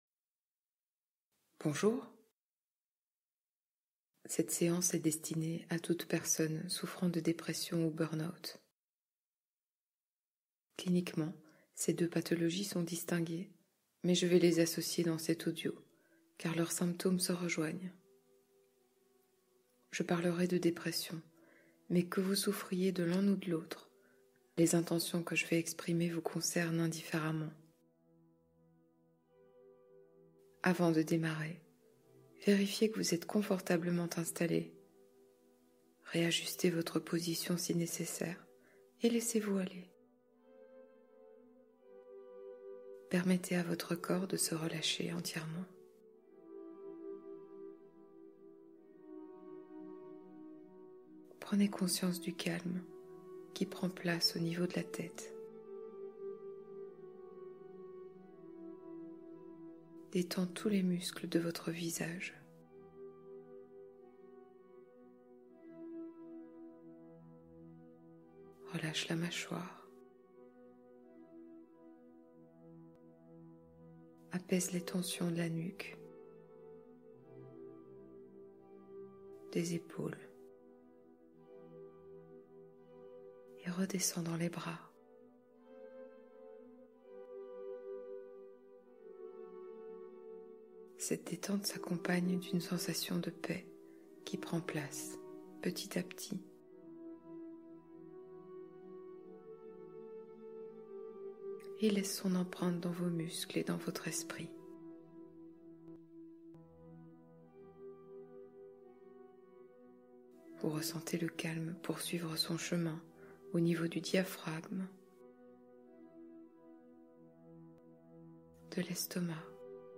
Stress et anxiété : musicothérapie et harmonisation